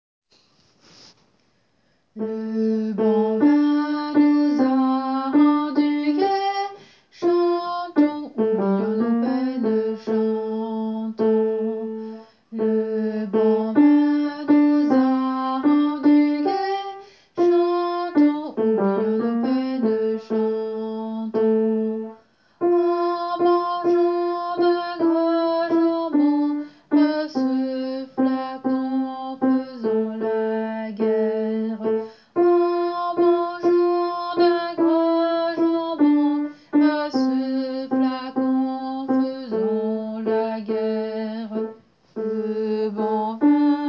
Alto :
tourdion-contratenor.wav